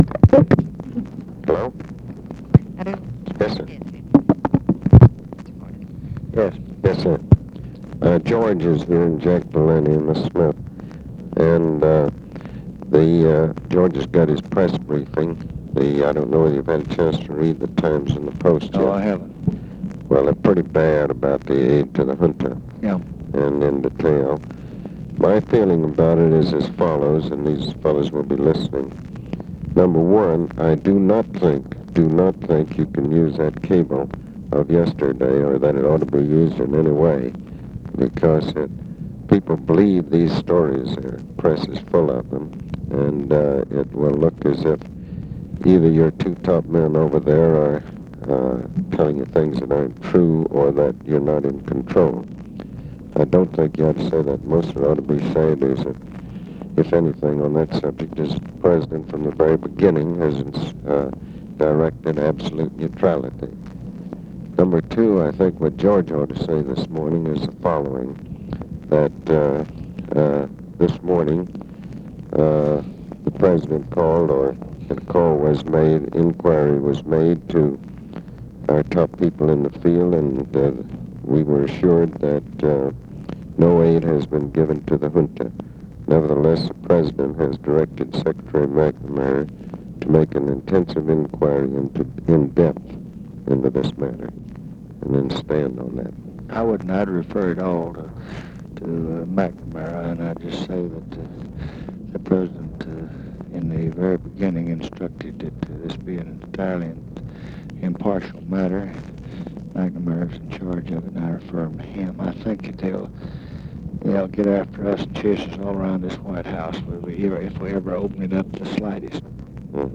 Conversation with ABE FORTAS, May 20, 1965
Secret White House Tapes